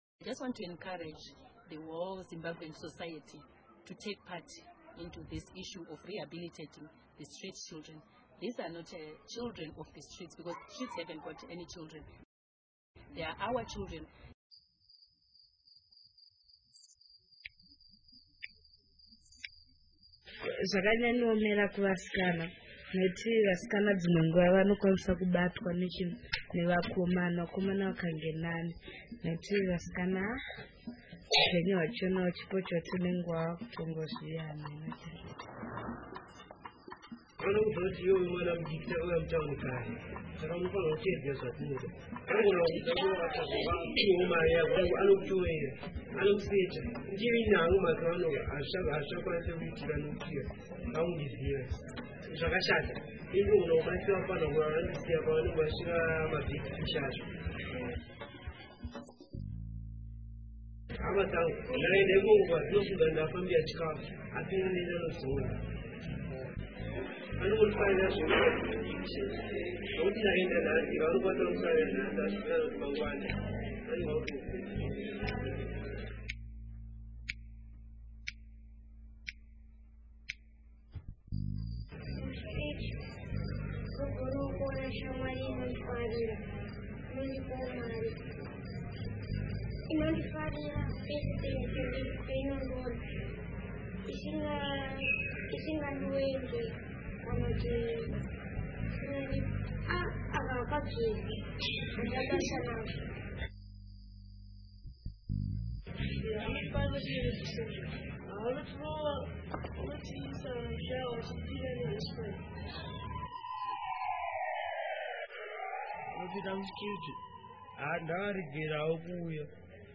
Five children talk about issues that impact on their lives in the street.
This transcript is derived from interviews conducted by Kubatana in Harare in September 2004.
streetchildren_shona_0409a.MP3